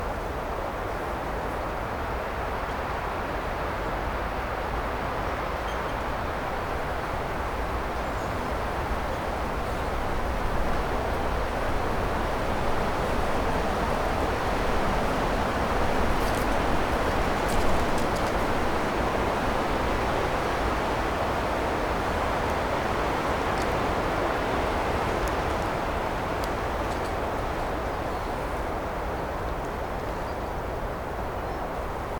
wind-2.ogg